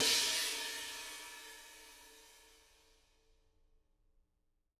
R_B China 01 - Room.wav